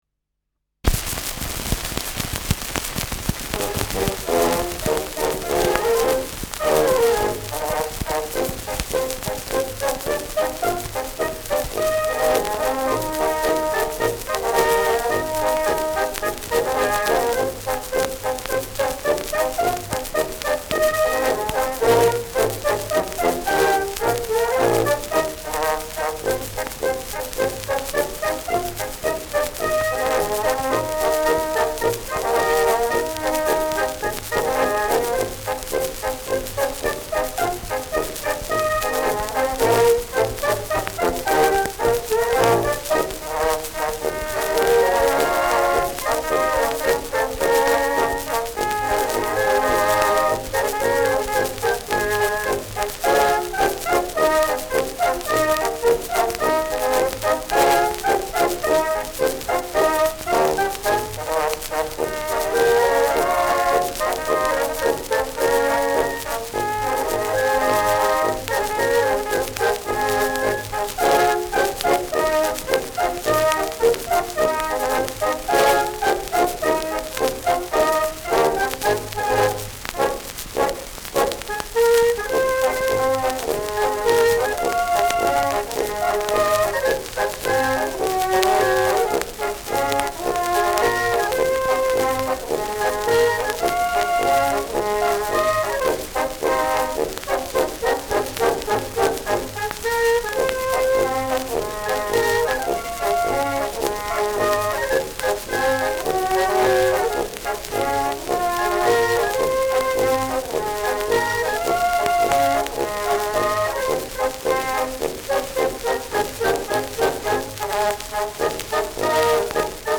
Schellackplatte
präsentes Rauschen
Böhmische Bauernkapelle (Interpretation)